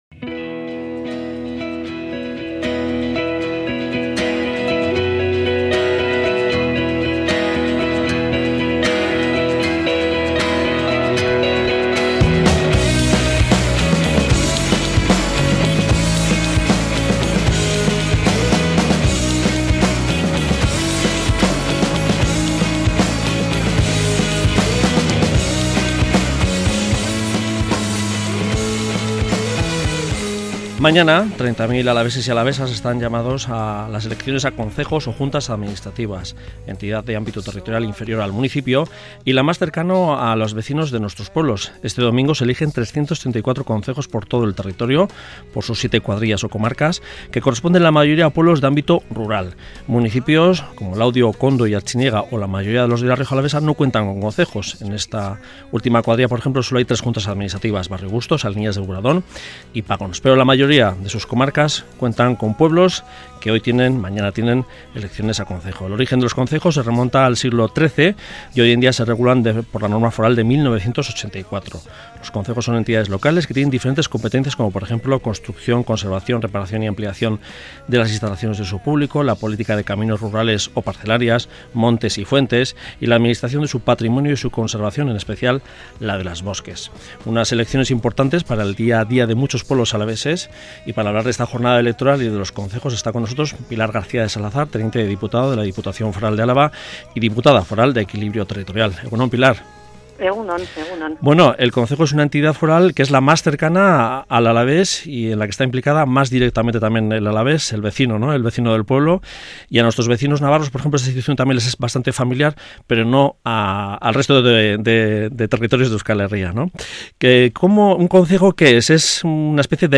Audio: Audio: Entrevista a Pilar García de Salazar, Tte. de Dipuatad y Diputada de Equilibrio Territorialsobre las elecciones a concejos en Araba 2018